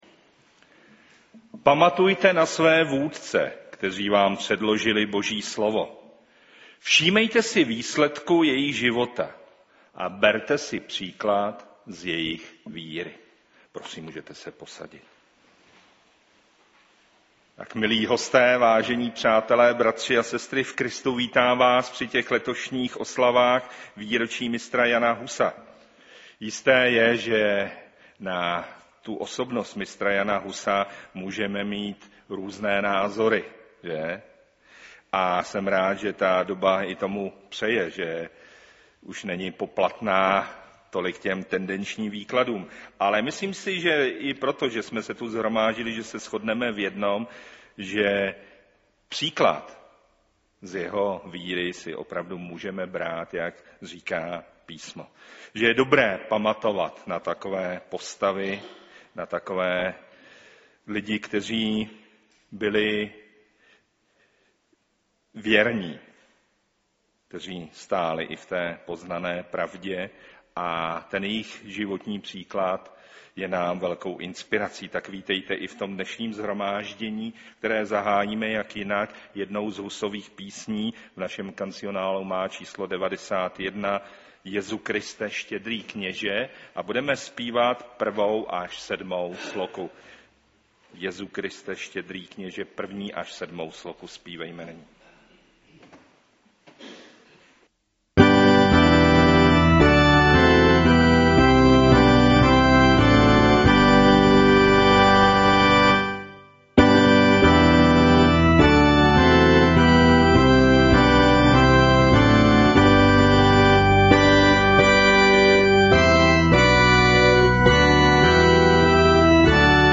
Ekumenická bohoslužba 2013